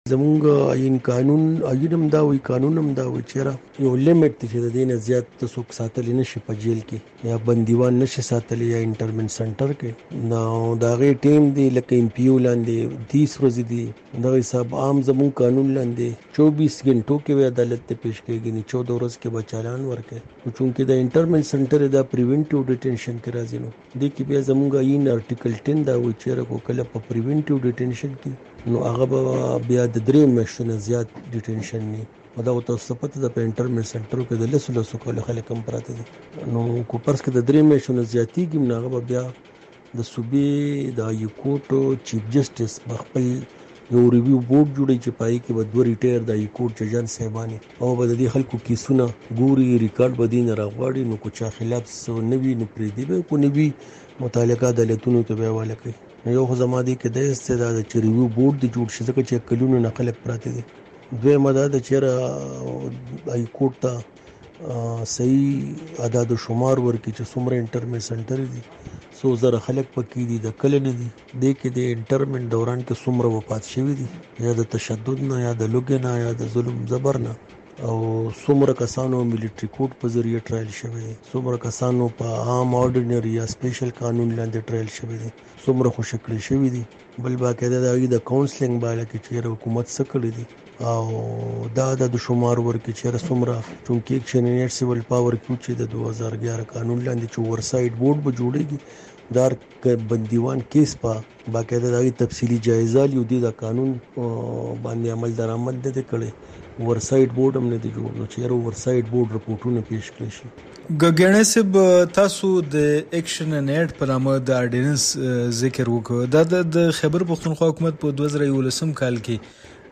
مرکه دلته واوری